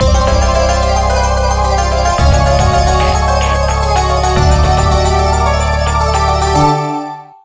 winjackpotnew.mp3